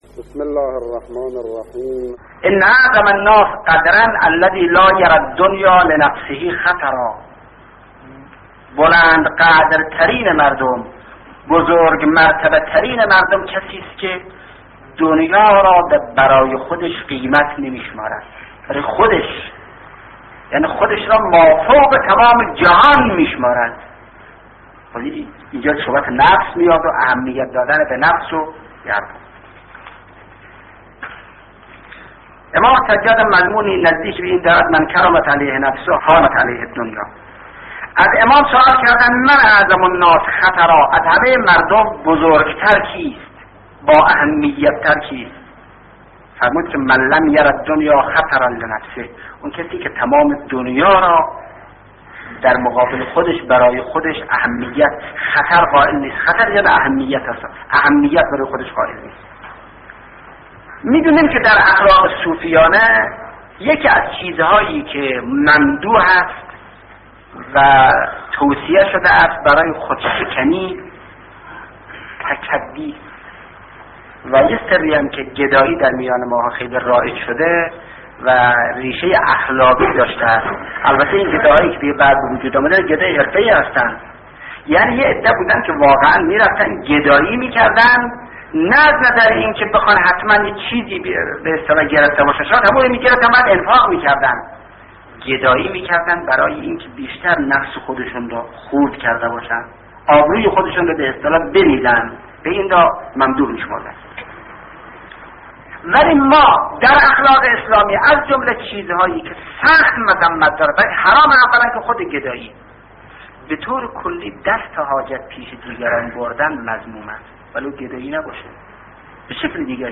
به گزارش خبرگزاری حوزه، استاد شهید مطهری در یکی از سخنرانی های خود به موضوع «اخلاق انسانی» پرداختند که تقدیم شما فرهیختگان می شود.